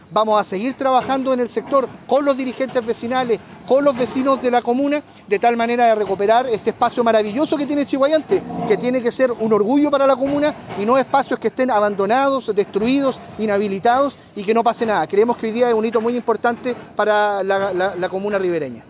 De igual manera, el delegado presidencial, Eduardo Pacheco, aseguró que con estas obras de demolición se mejorará el sector, la convivencia de los vecinos y con ello también las condiciones de seguridad.